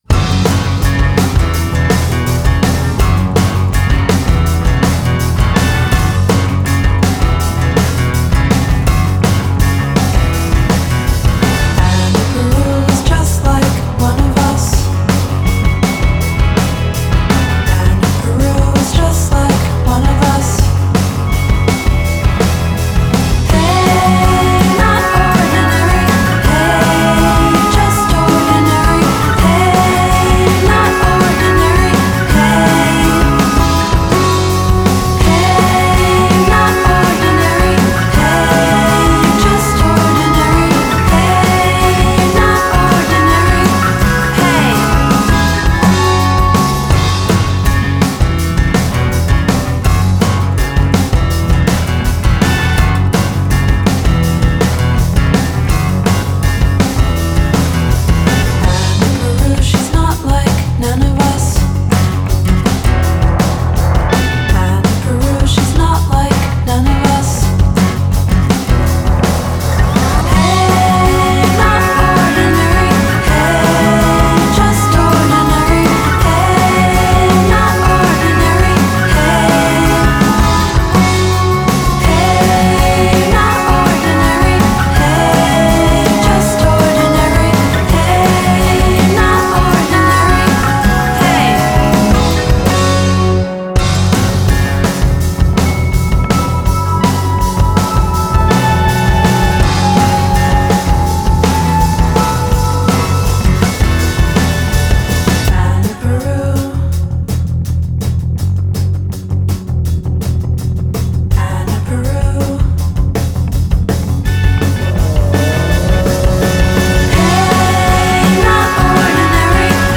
Genre: Indie Pop-Rock / Indie-Folk /